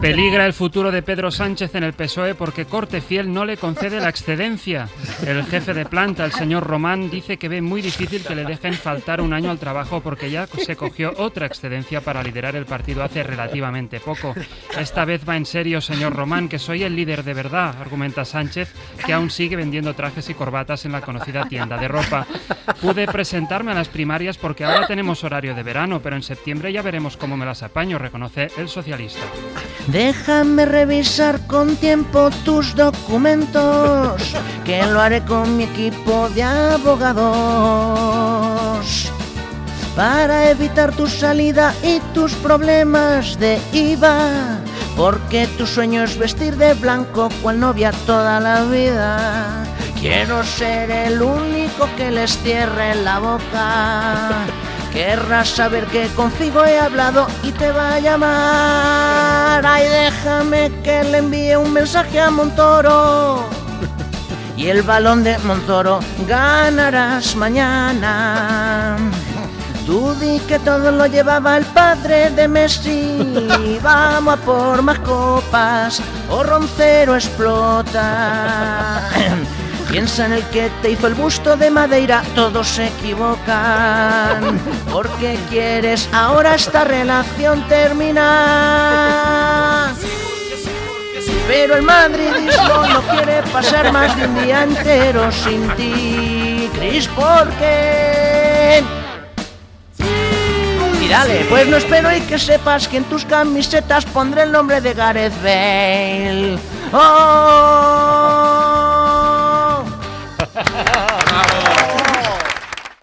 Secció humorística "Todo por la radio ".
Entreteniment